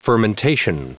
Prononciation du mot fermentation en anglais (fichier audio)
Prononciation du mot : fermentation
fermentation.wav